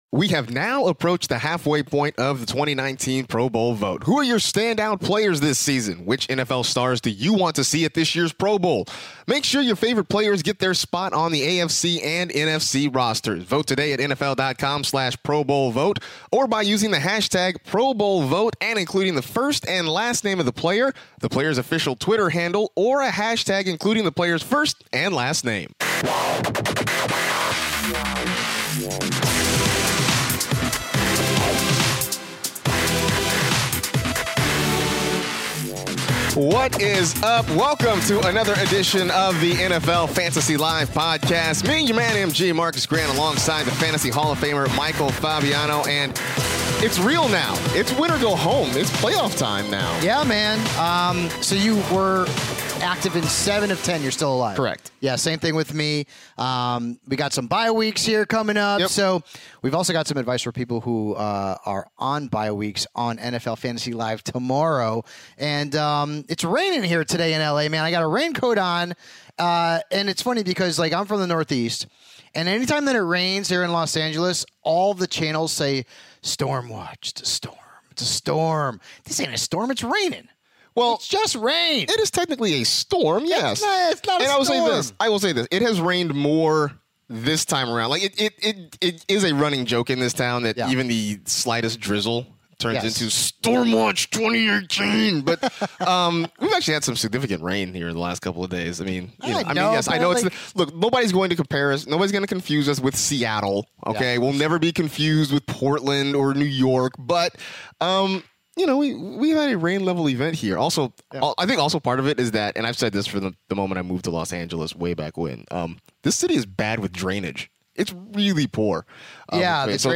are in studio to preview everything fantasy football for Week 14!